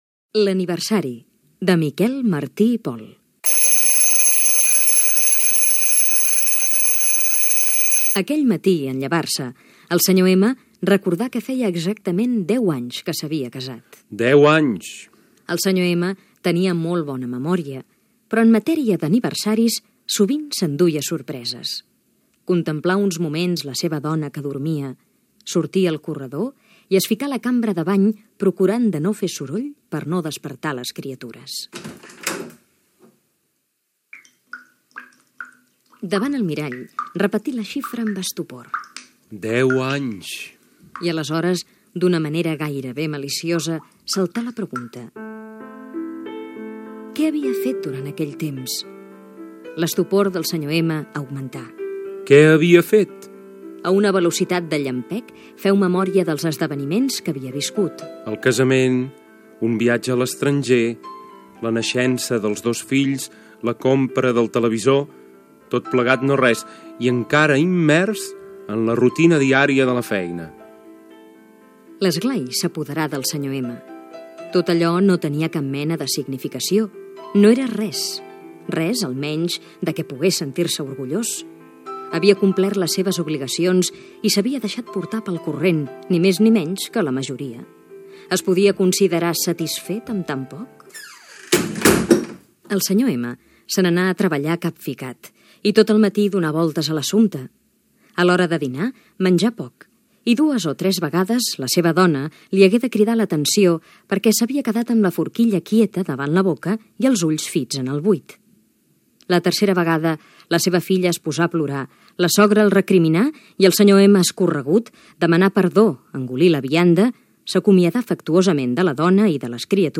Adaptació radiofònica de la rondalla "L'Aniversari", de Miquel Martí i Pol.
Infantil-juvenil